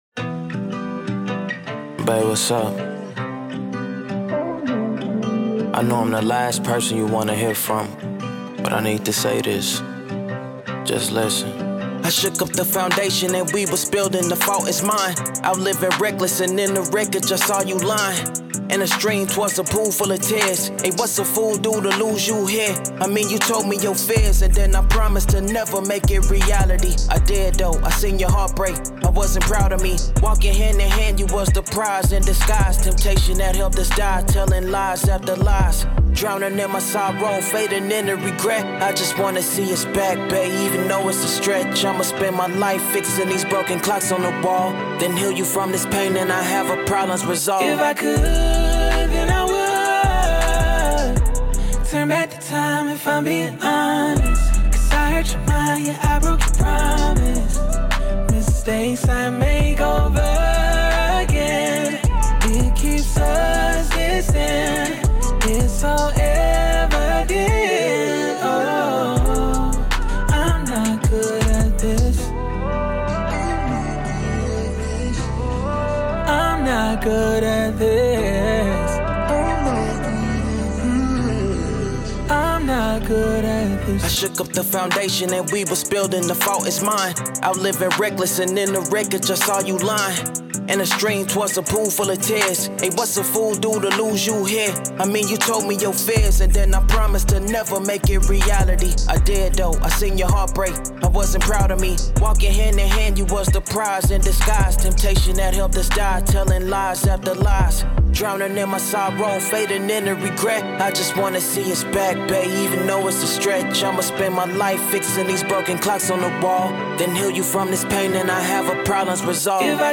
R&B, Hip Hop
G Major